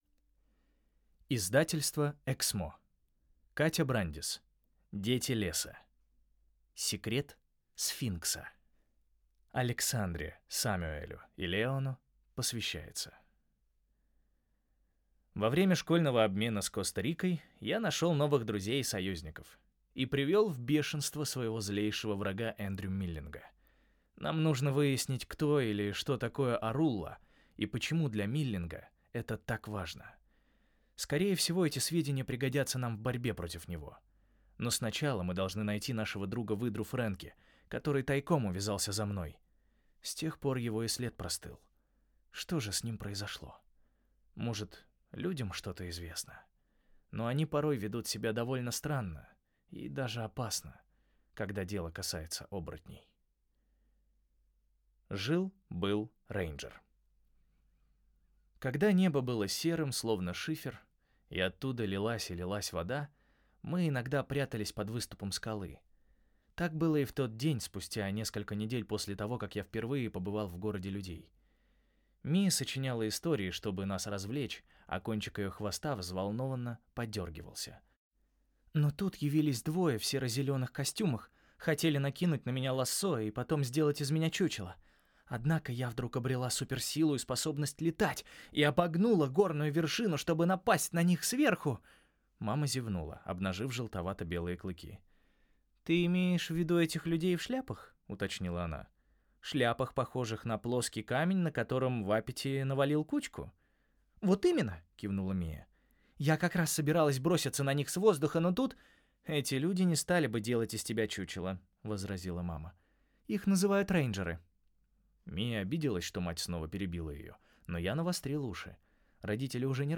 Аудиокнига Секрет сфинкса | Библиотека аудиокниг